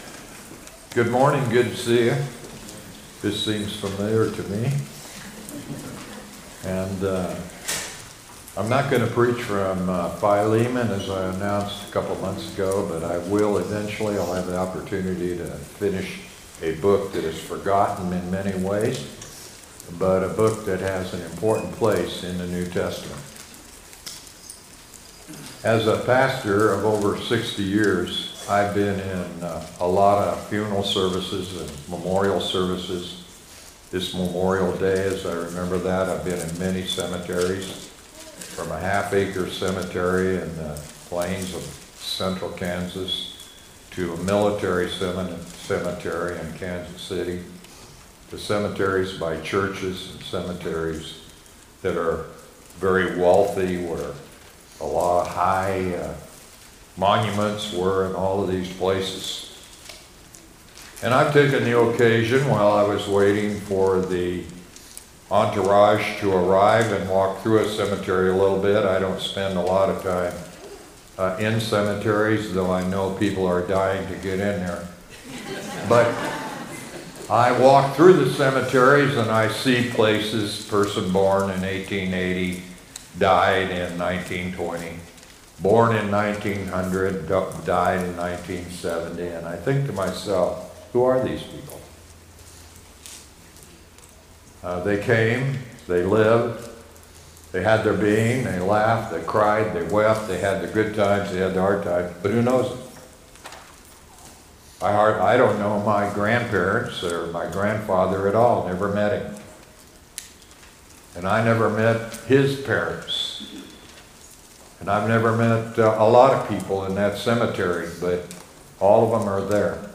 Sunday Morning | 1 Thessalonians 4:13–18
sermon-5-25-25.mp3